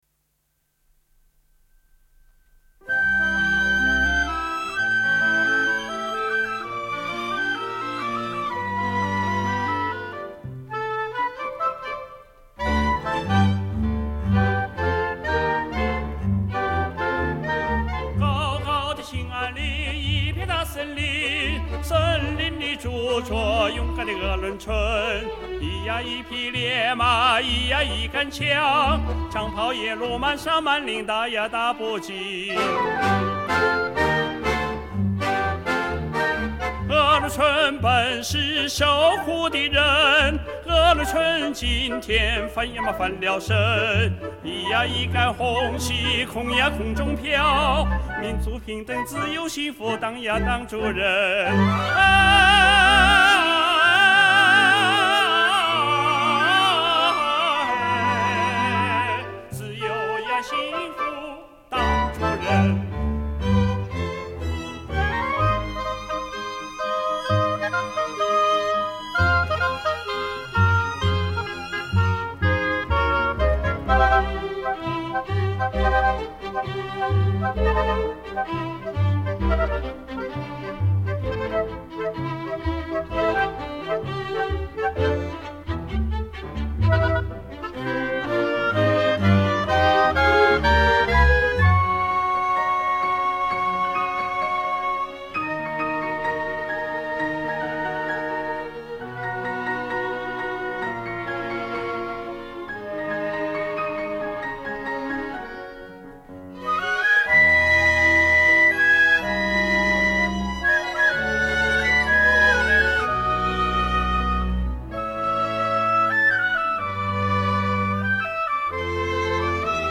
《鄂伦春小唱》 内蒙鄂伦春族民歌
伴奏：上海交响乐团小乐队